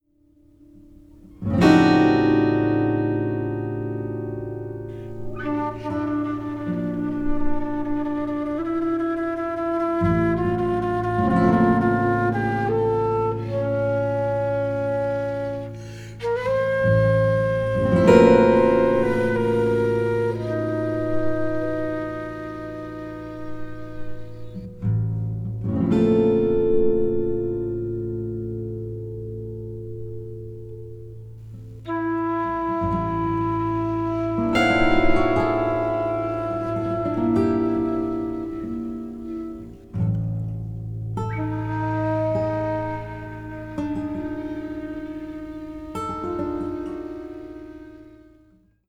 Flute, Alto flute, Bansuri flutes
16-string Classical guitar